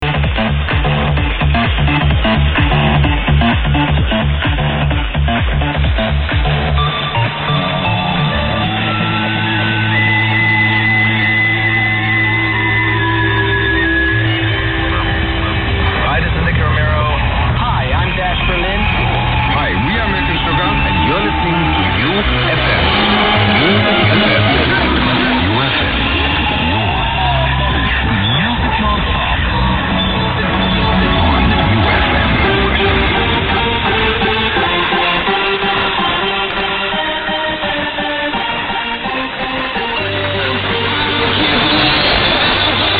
This unusual station was reported as a Russian station, heard late Monday night / Tuesday morning on 1395.